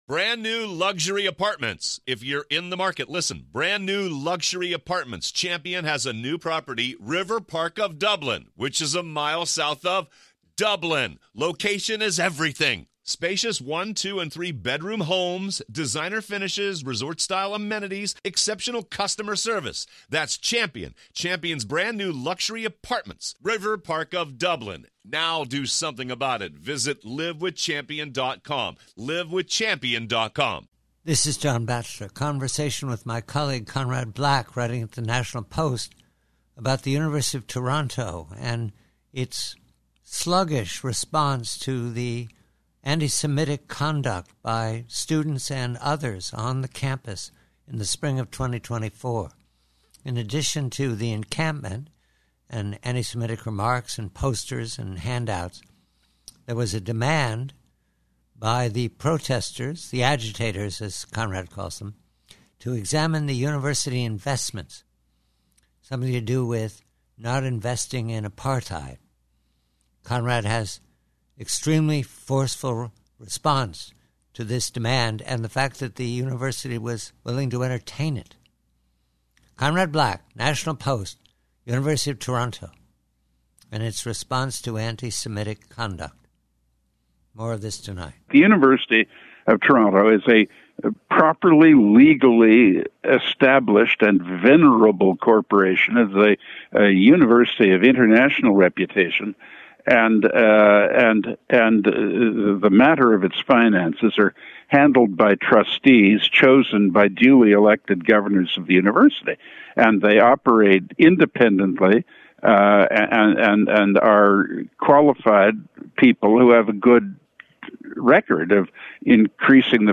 PREVIEW: TORONTO: ANTISEMITISM: Conversation with colleague Conrad Black of the National Post re the sluggish response of the University of Toronto administration to the antisemitic agitators who camped on private property and demanded to see the books.